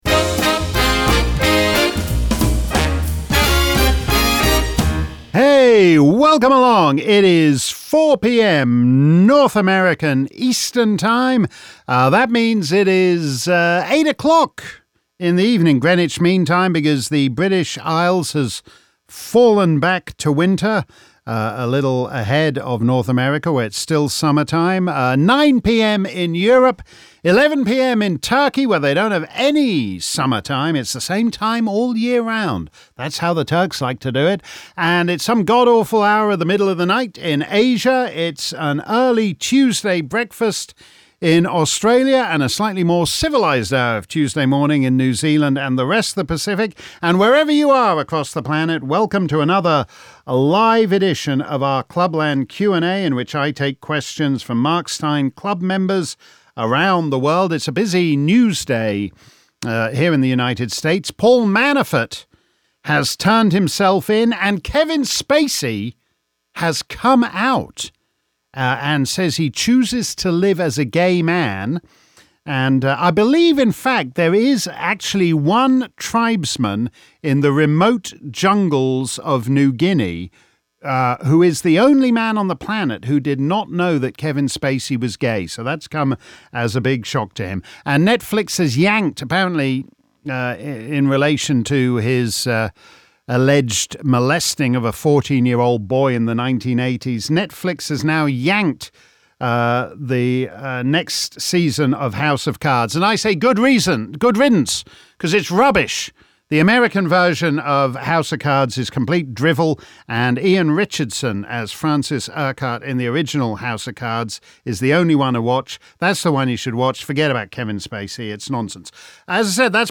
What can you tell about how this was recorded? If you missed our livestream Clubland Q&A earlier today, here's the action replay.